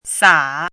chinese-voice - 汉字语音库
sa3.mp3